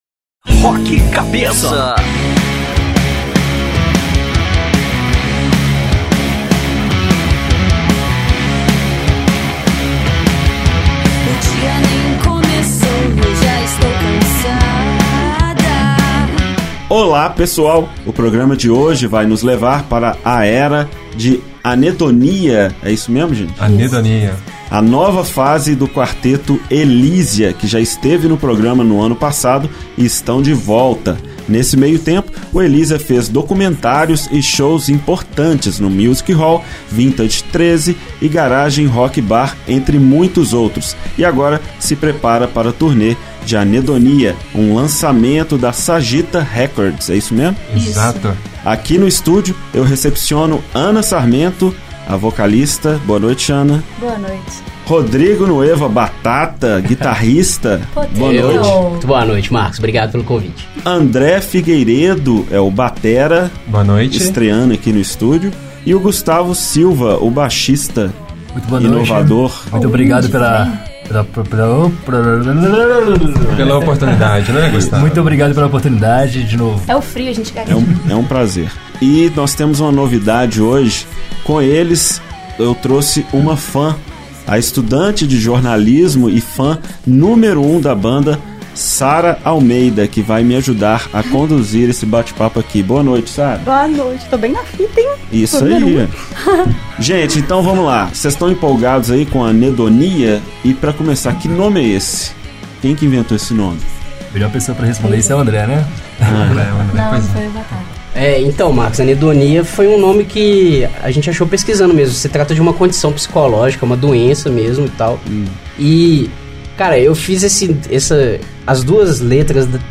Ouça agora a entrevista com a banda Elizia que foi ao ar no Rock Cabeça na Inconfidência FM: